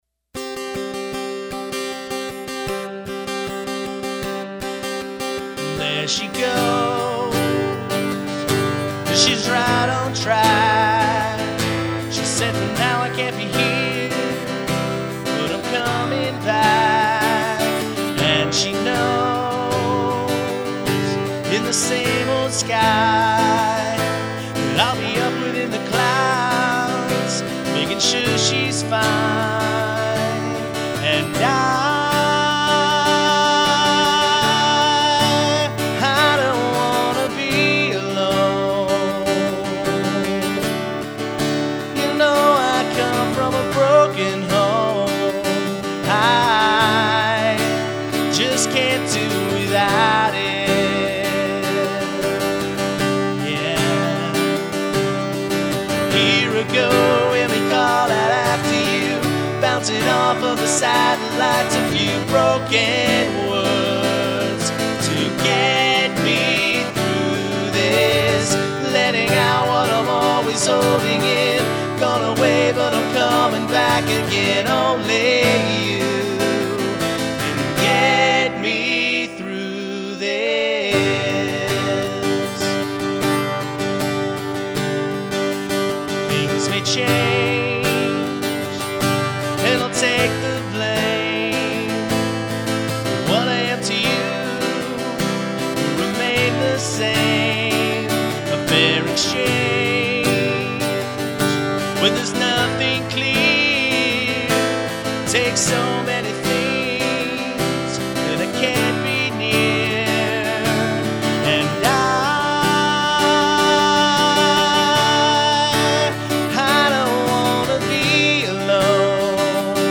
Home/Studio Recordings